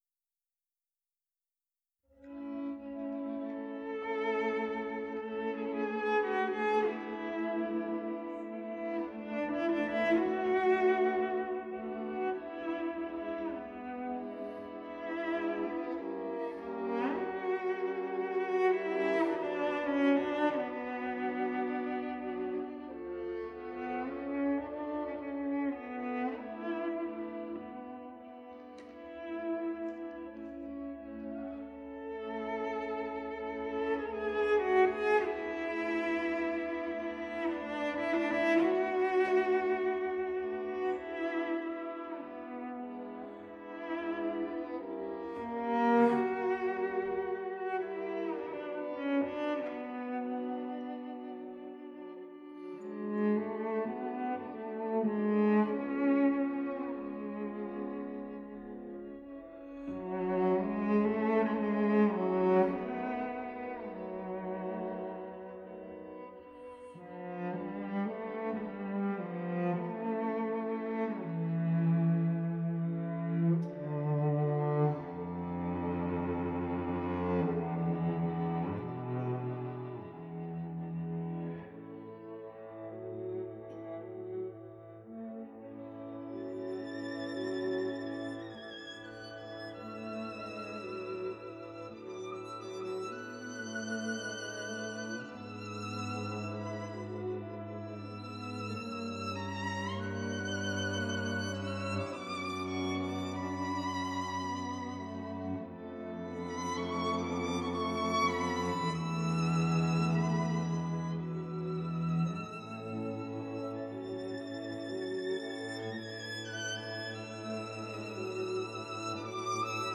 LiveARTS String Quartet